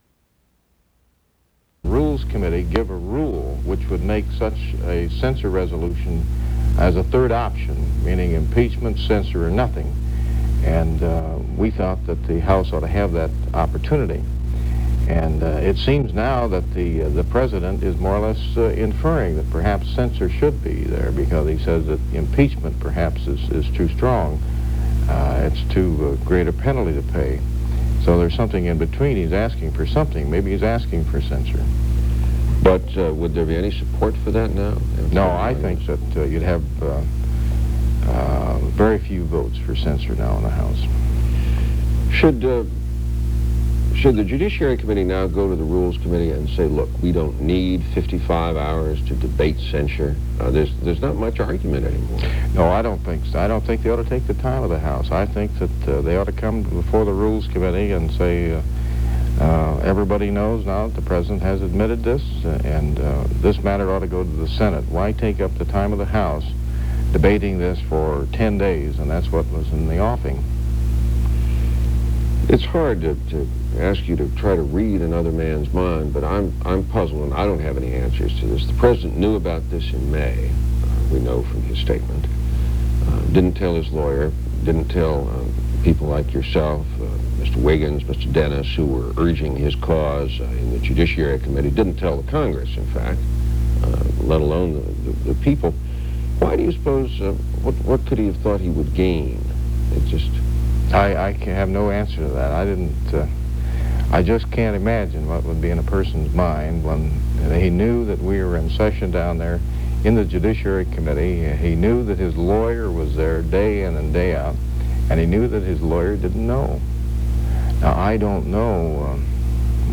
Excerpt of an interview with U.S. Representative Delbert Latta on the impeachment of President Richard Nixon